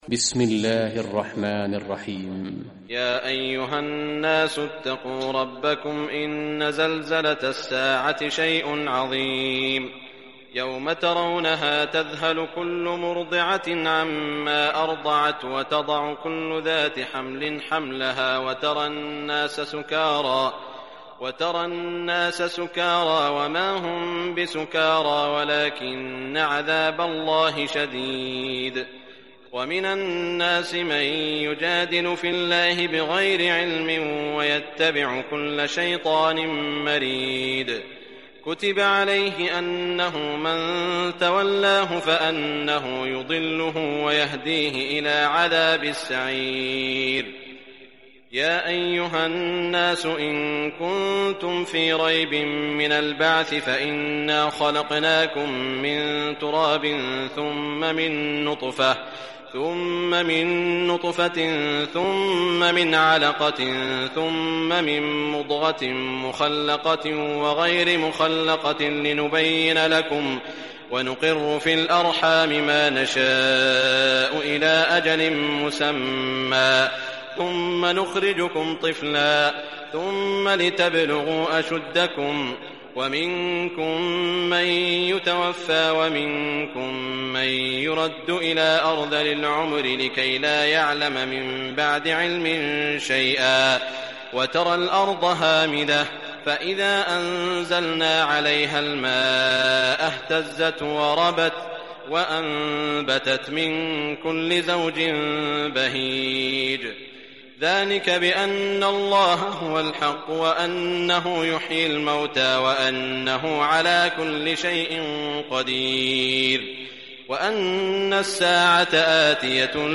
Surah Al-Hajj Recitation by Sheikh Shuraim
Surah Al-Hajj, listen or play online Arabic tilawat in the voice of Imam e Kaaba Sheikh Shuraim.